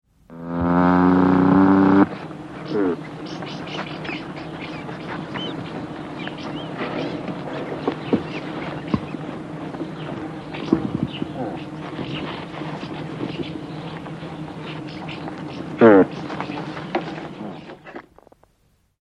Звуки нутрии
Рычание самцов